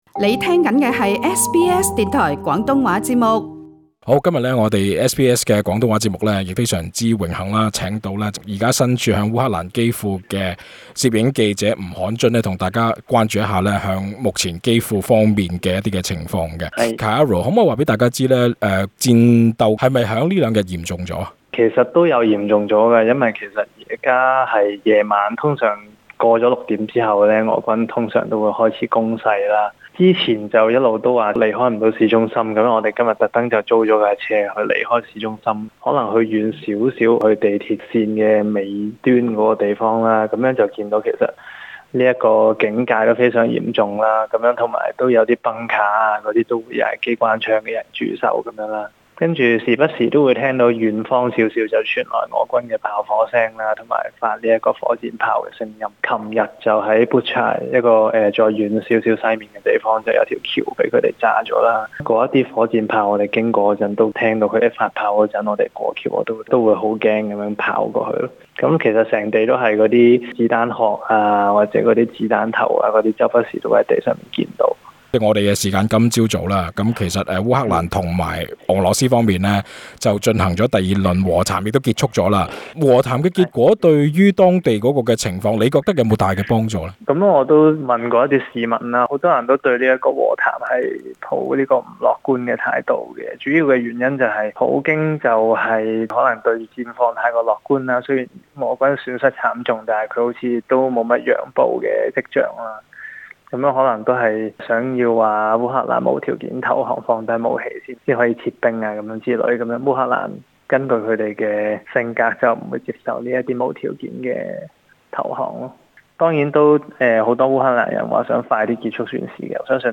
【專訪】香港記者深入烏克蘭戰區採訪 留守至最後一刻